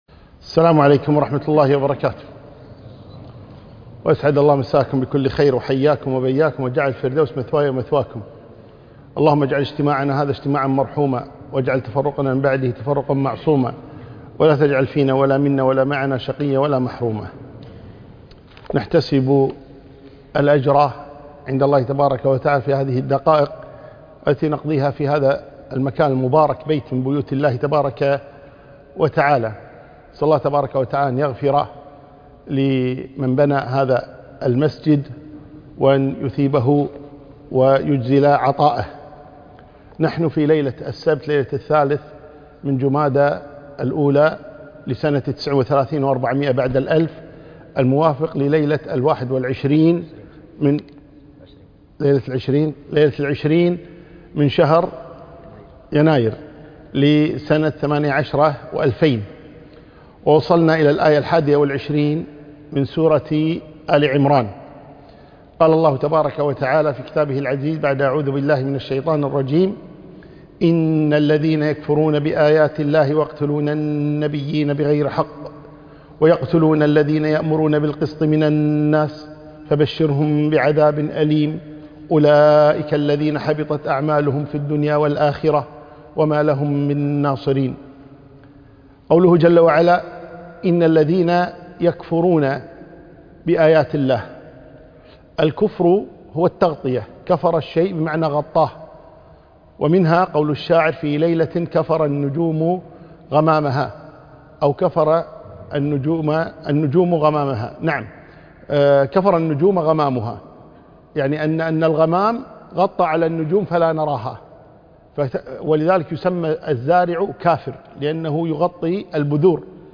كلمة لقاء الجمعة